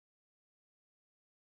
• Висока невизначеність (Висока KLD) \\rightarrow Тріск Баркгаузена. Звук магнітних доменів, що різко вирівнюються. Звучить як палаючий статичний шум.
• Розсіювання енергії \\rightarrow Низькочастотний гул. Низькочастотний гул машини, яка важко працює, щоб ігнорувати сумніви.
Послухайте тріск на піку.